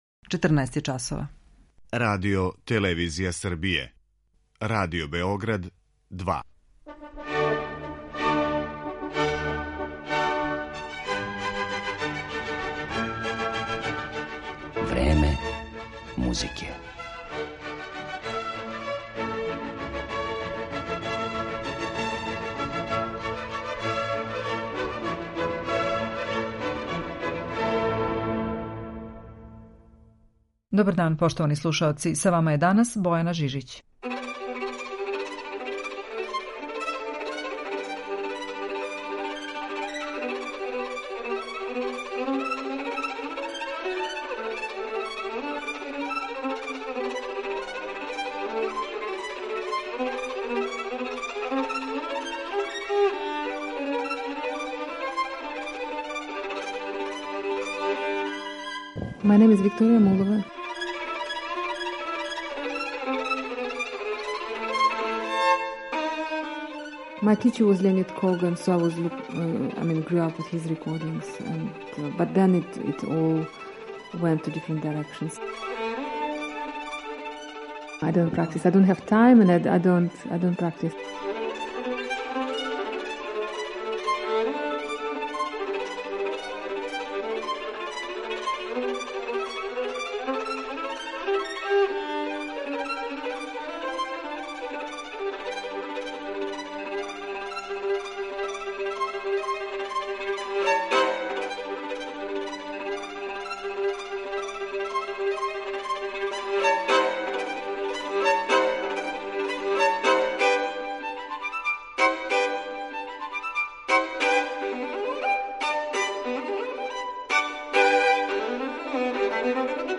Данашња емисија је посвећена овој славној руској уметници, коју ћемо представити и као солисту и као камерног музичара. Изводиће композиције Беле Бартока, Александра Глазунова, Јохана Себастијана Баха и Франца Шуберта, а емитоваћемо и интервју